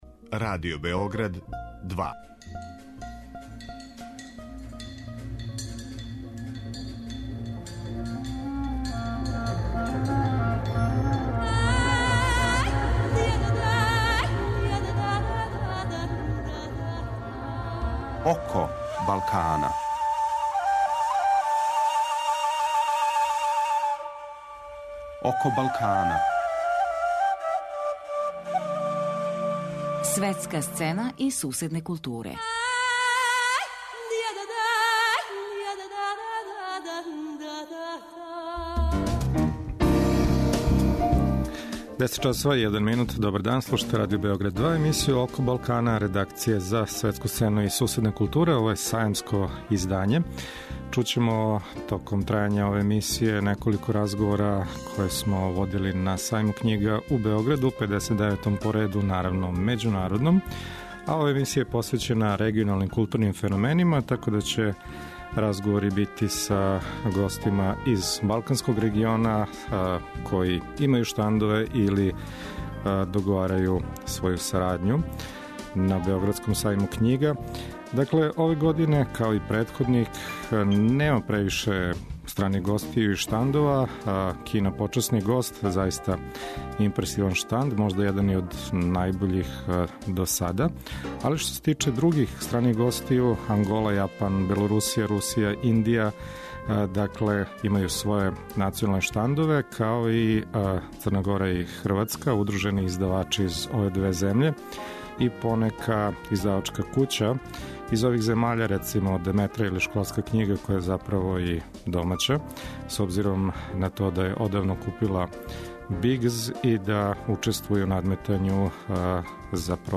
Данашње издање емисије се реализује са 59. Међународног сајма књига у Београду.